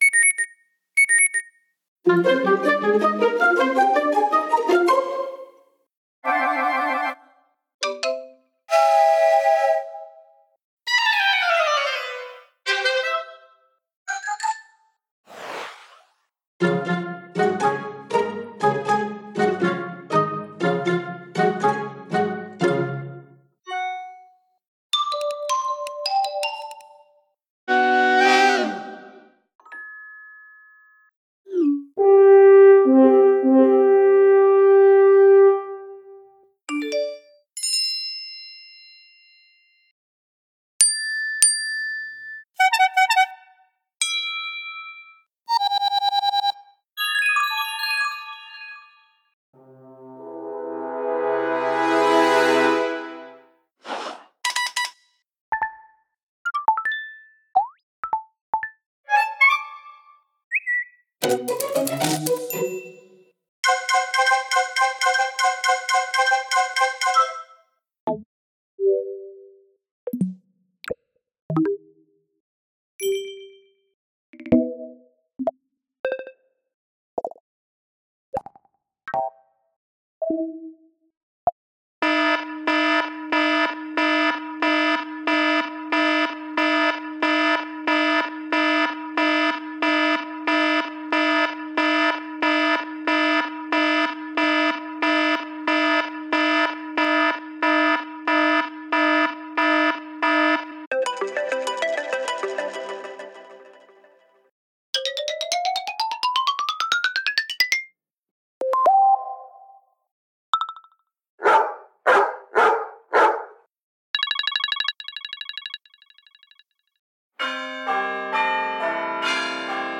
macOSsystemsounds
macOSSystemSoundsCombined.mp3